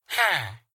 MinecraftConsoles / Minecraft.Client / Windows64Media / Sound / Minecraft / mob / villager / yes1.ogg
yes1.ogg